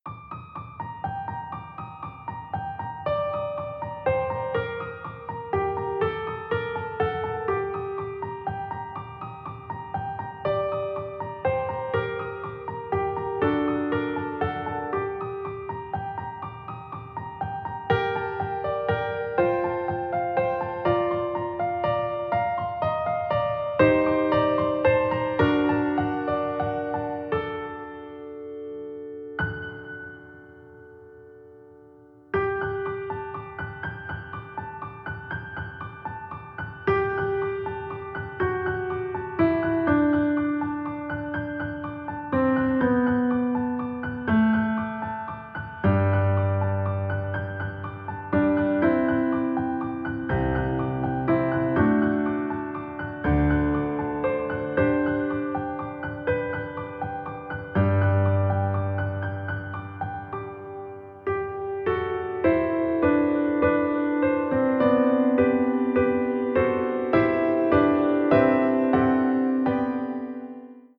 for intermediate piano
Voicing/Instrumentation: Piano Solo